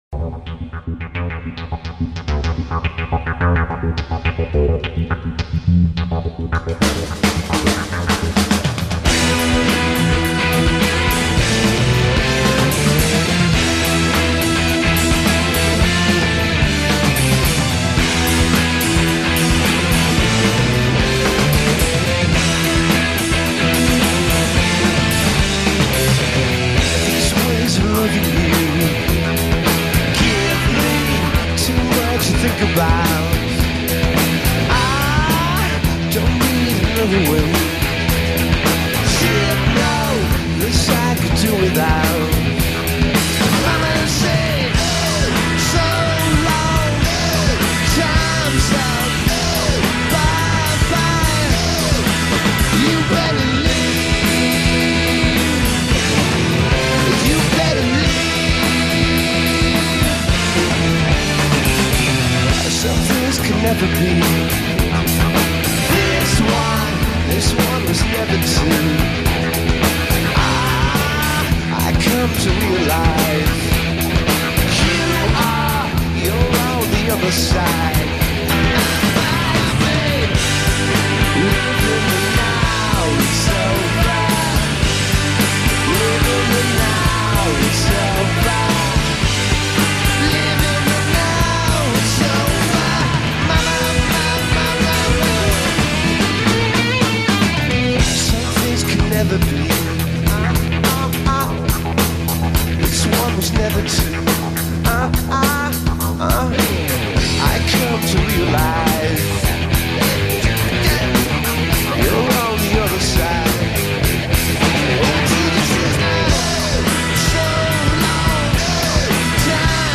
Neo-Psych/quasi-Baggy/indie/Alt